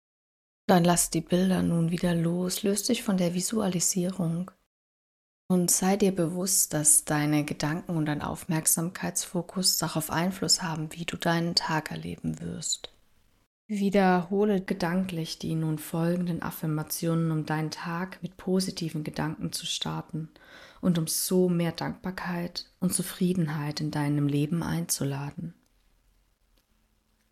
YogaNidra-Positiver-Tagesstart-Hoerprobe.mp3